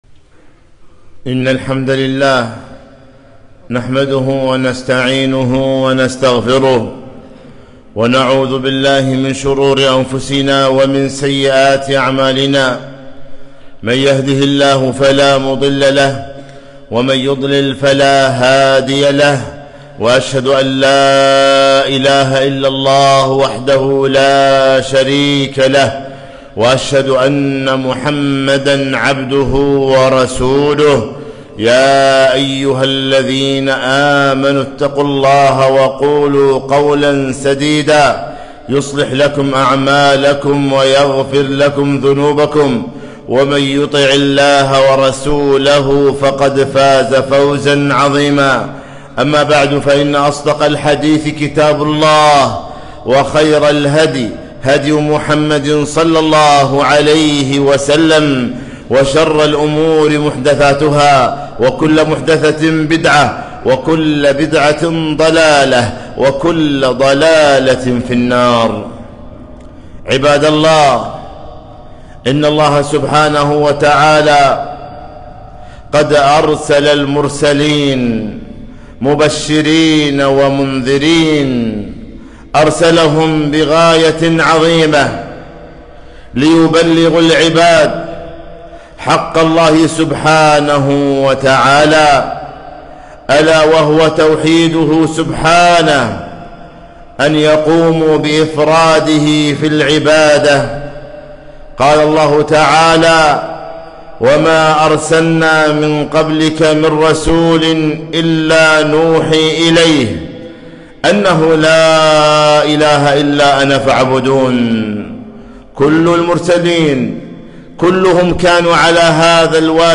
خطبة - هذه عقيدتنا في المسيح عليه السلام